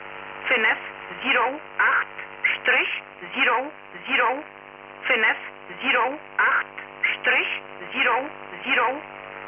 Added samples of a 100 Bd / 625 Hz FSK waveform used by Polish intelligence for one-way broadcasts via HF.